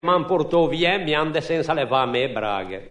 Avvertibilissime e chiarissimamente denotanti il parlato genovese genuino.
In questo caso particolare, la “crasi” “produce” - nella pronuncia - semplicemente un'unica vocale lunga.